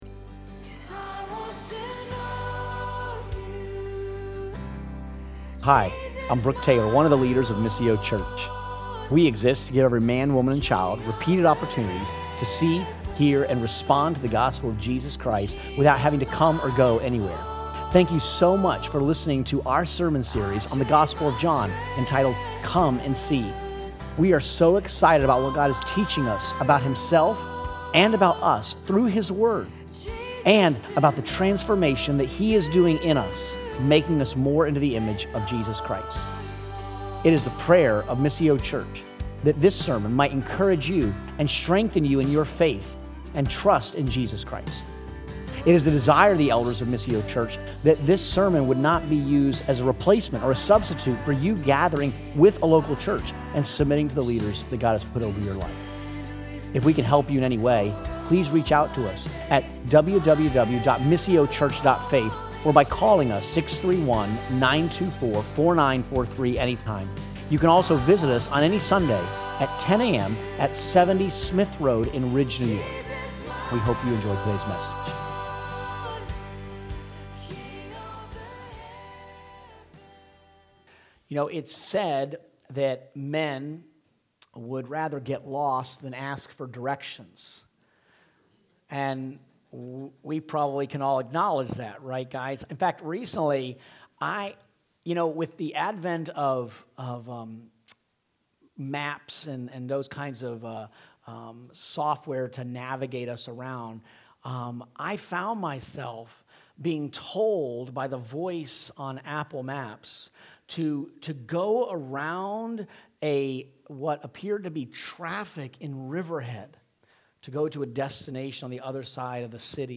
Sermons | Missio Church